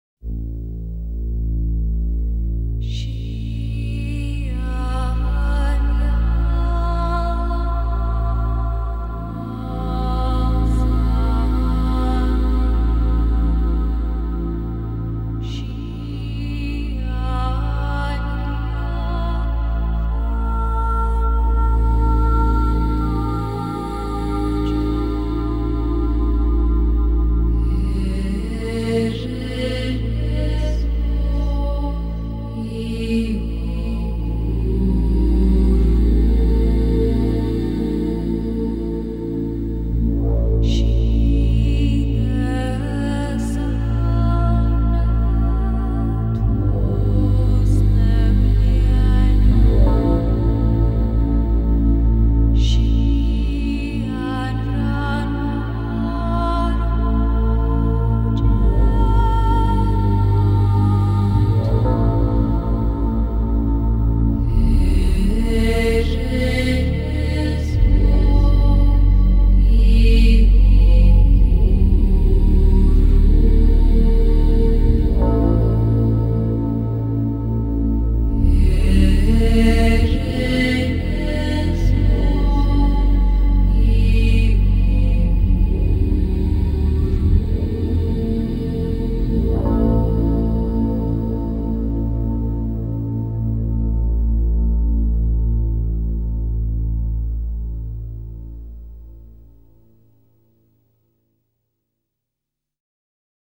장르: Electronic
스타일: Modern Classical, Minimal, Ambient